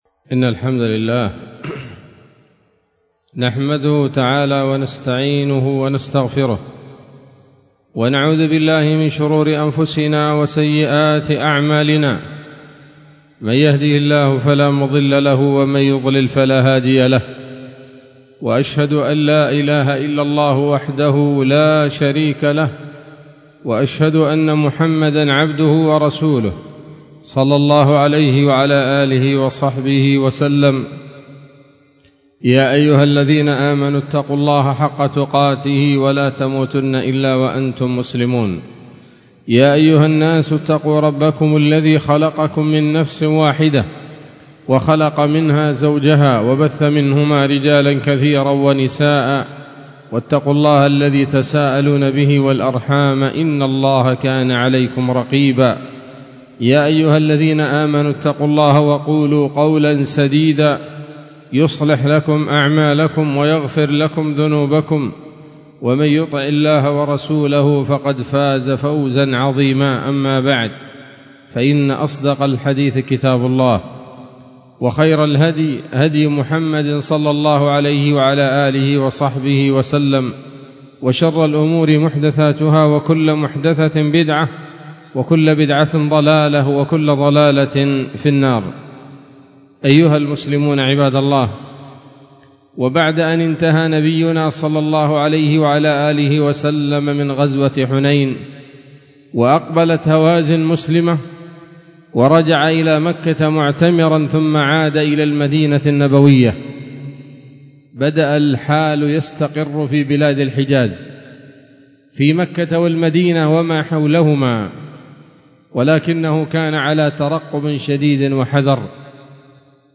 خطبة جمعة بعنوان: (( السيرة النبوية [31] )) 19 صفر 1446 هـ، دار الحديث السلفية بصلاح الدين